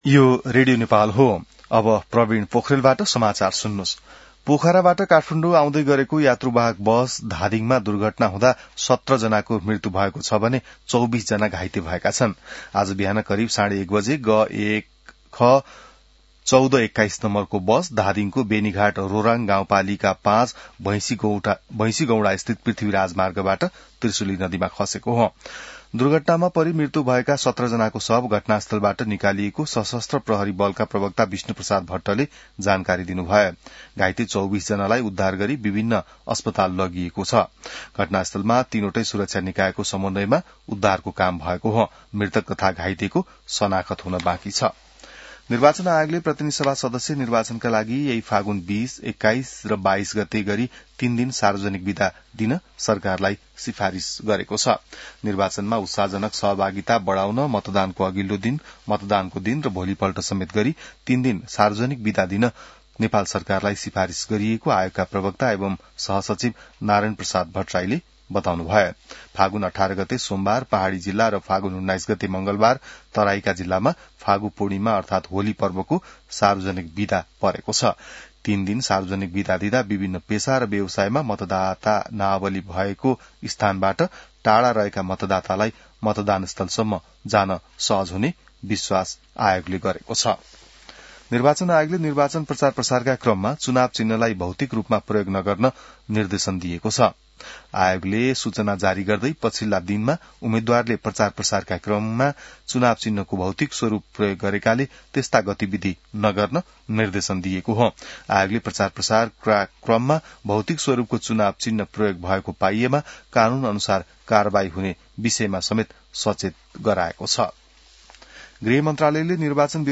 बिहान ६ बजेको नेपाली समाचार : ११ फागुन , २०८२